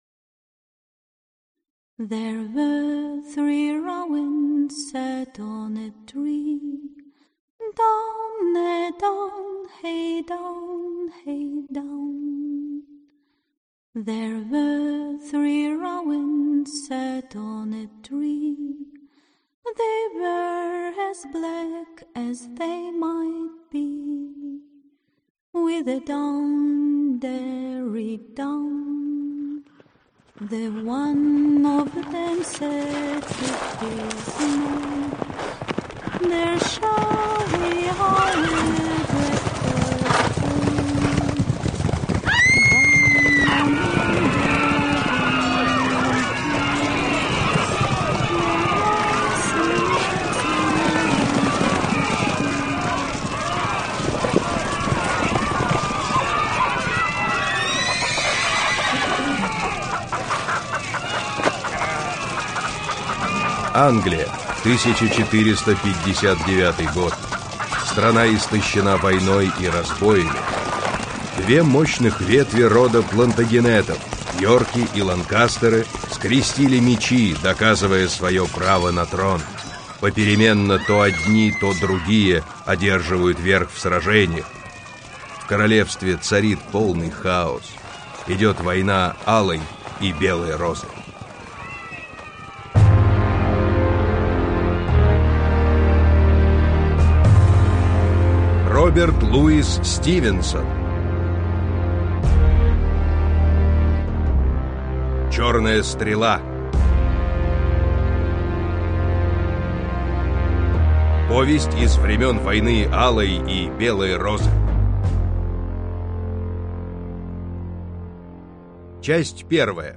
Аудиокнига Черная стрела (спектакль) | Библиотека аудиокниг